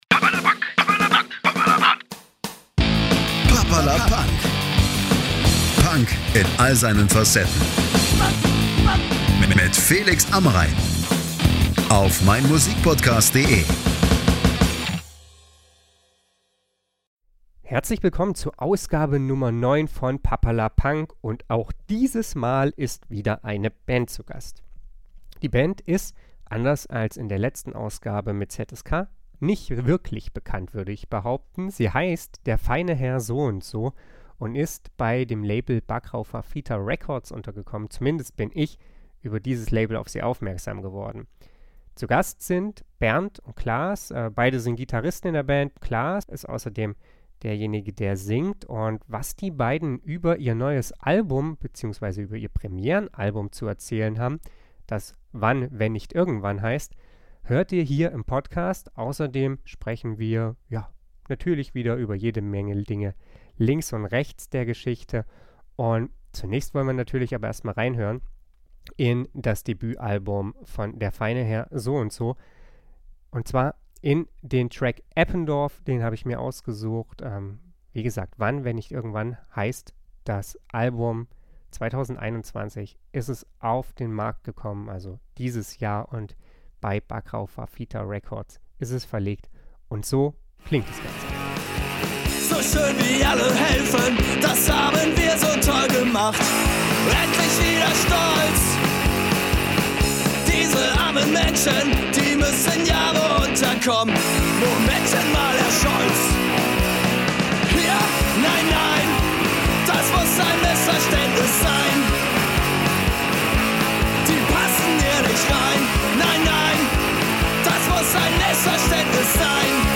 Den Podcast haben wir am 17.02.2021 via StudioLink aufgenommen.